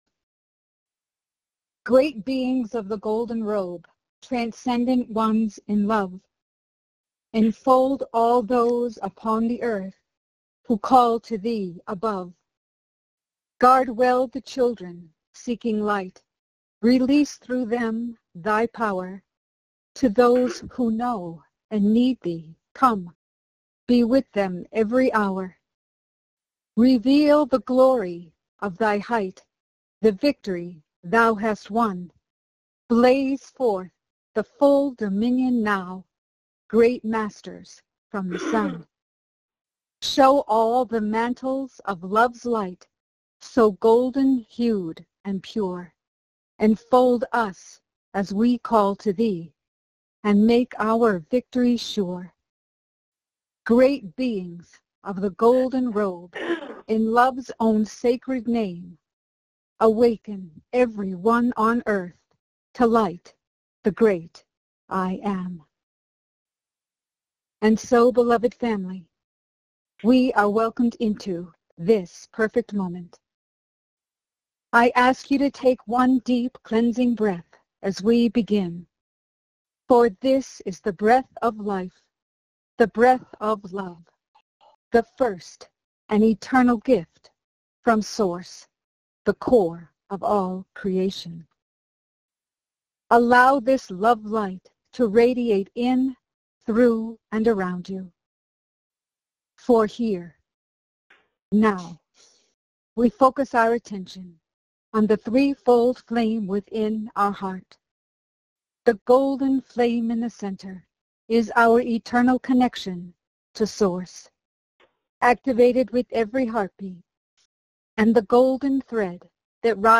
Join and follow in group meditation along with Archangel Michael.